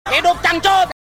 Efek Suara Hidup Cangcut
Genre: Efek suara Tag: Efek suara , nada dering lucu , nada dering TikTok Ukuran file: 458 KB Dilihat: 5537 Views / 56 Downloads Detail: Hidup Cangcut!
efek-suara-hidup-cangcut.mp3